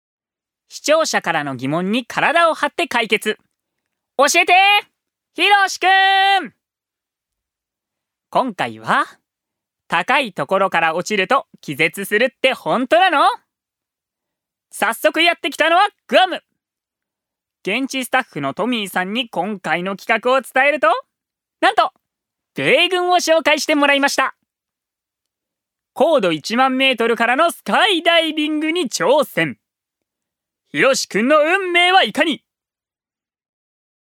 ジュニア：男性
ナレーション２